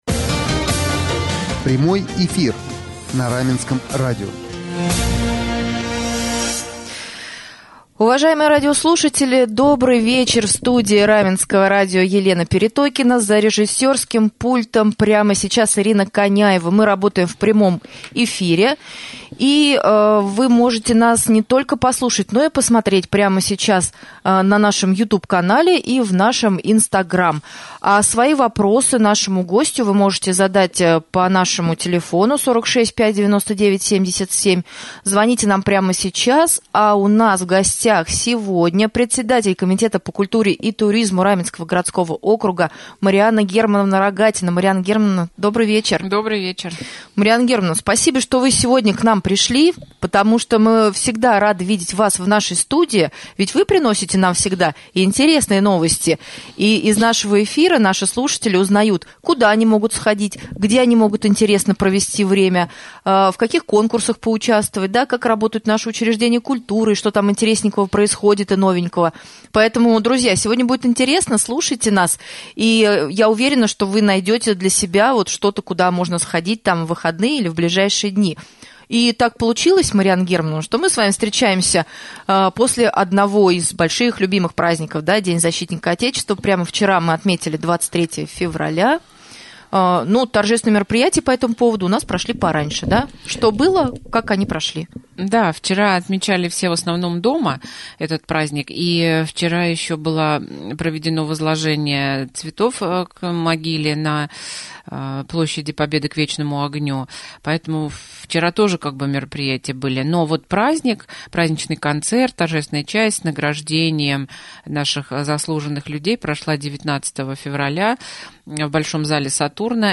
Ответы на эти и другие вопросы о работе Комитета по культуре и туризму Раменского г.о. слушайте в прямом эфире с председателем Комитета Марианной Рогатиной, который состоялся на Раменском радио 24 февраля.